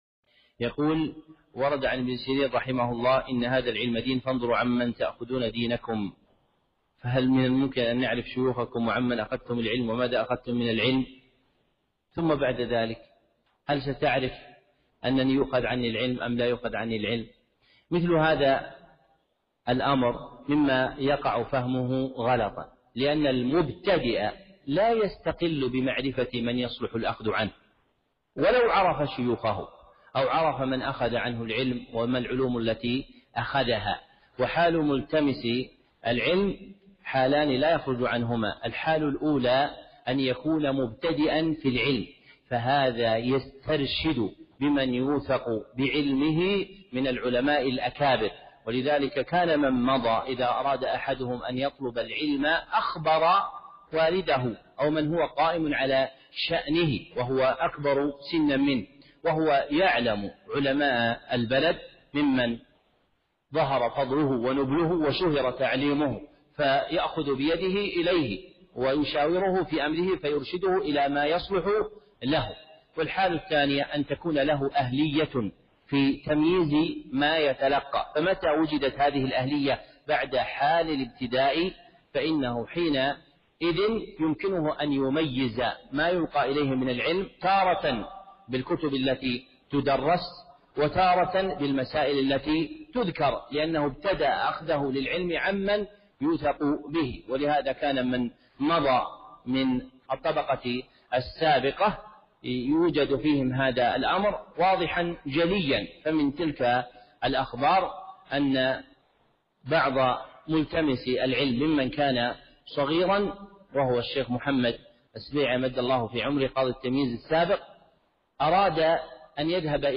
(موعظة بليغة)